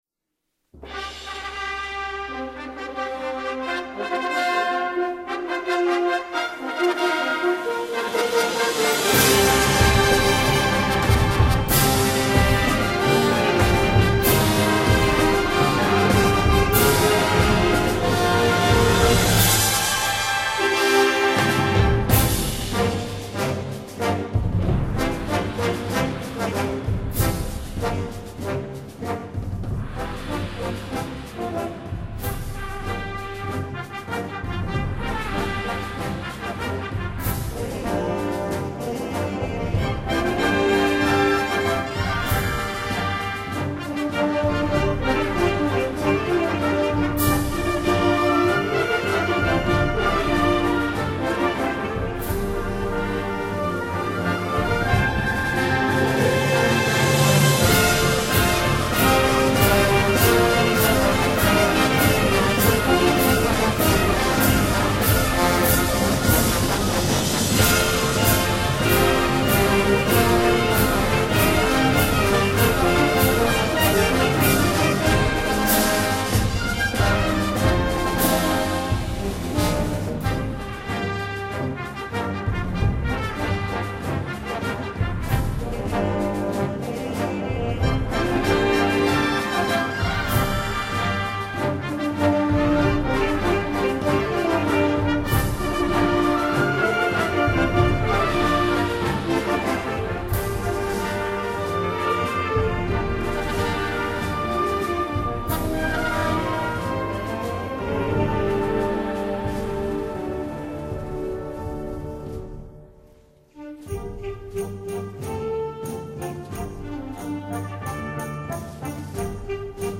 Marxa Cristiana